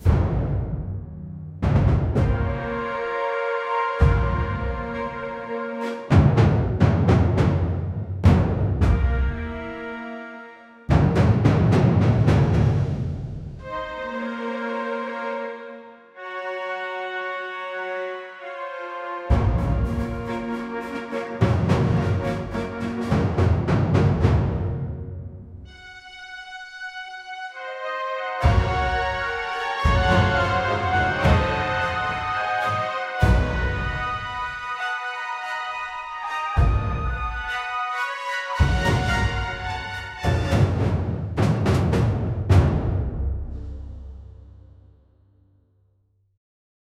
military-medal-ceremony-m-cwm3m2rb.wav